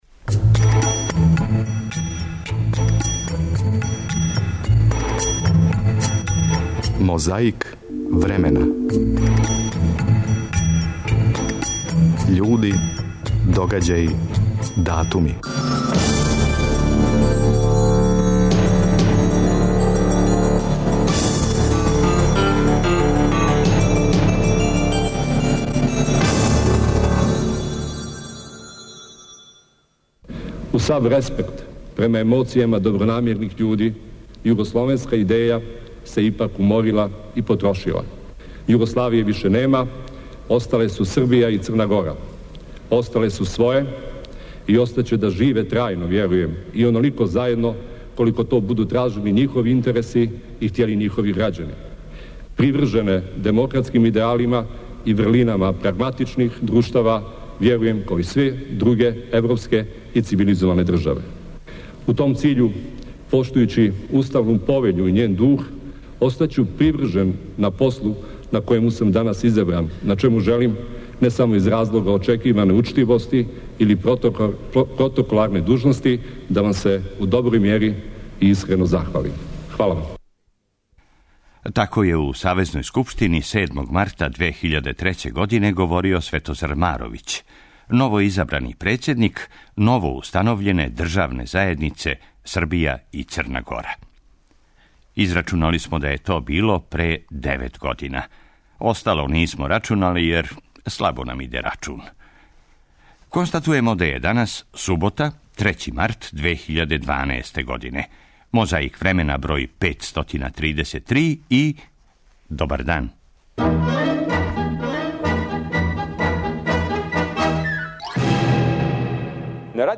Ватрено су се расправљали, на граници увреде и понекад и преко ње - Велимир Илић, Борис Тадић, Ивица Дачић и Томислав Николић.